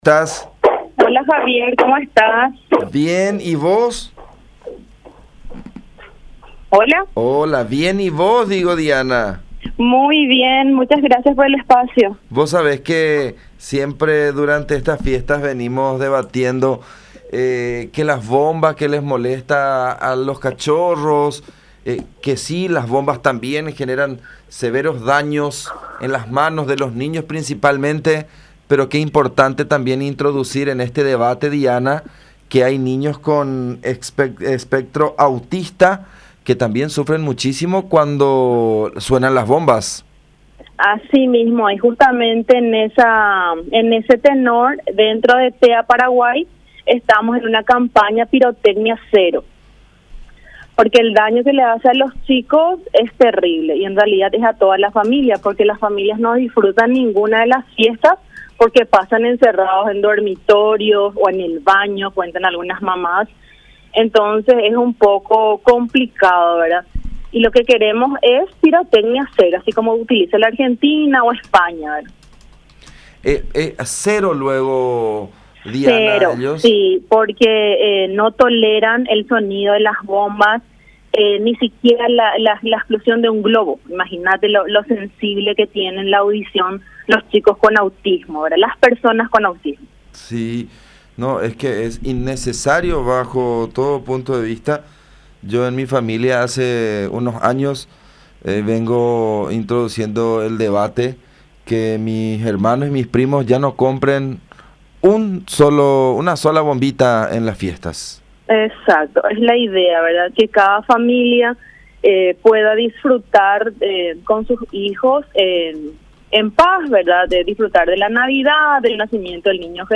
en comunicación con La Unión R800 AM